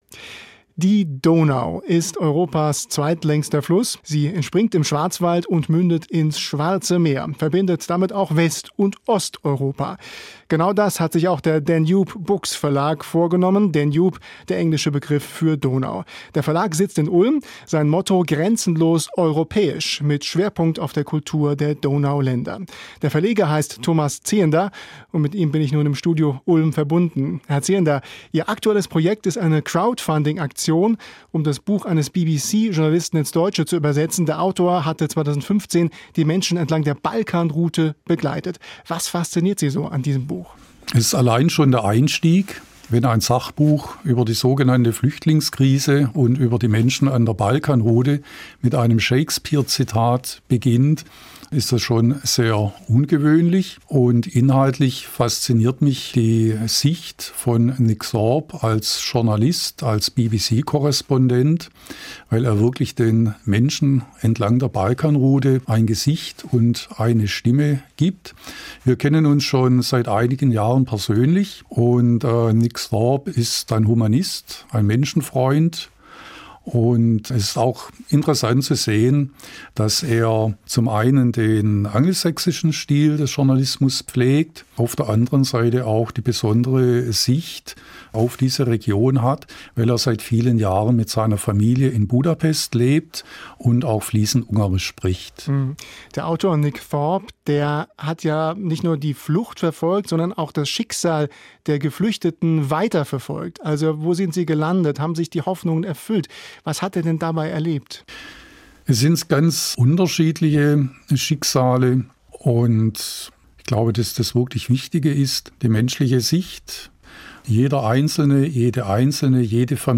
Rundfunk-Interview